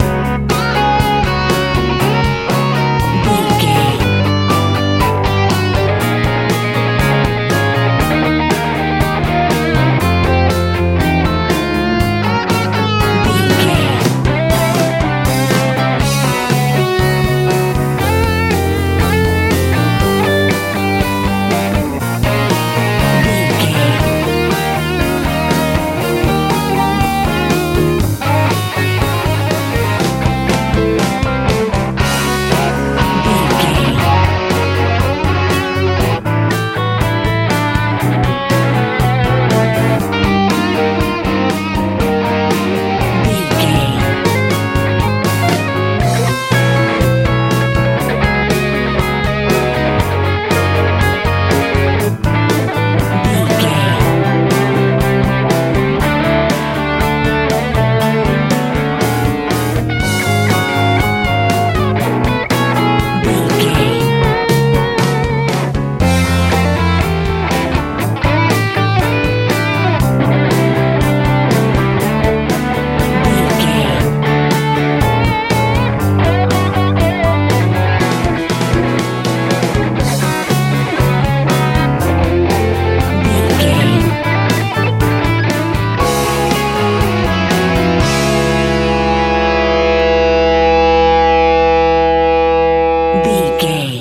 Ionian/Major
D♭
bright
hopeful
energetic
electric guitar
bass guitar
drums
driving
joyful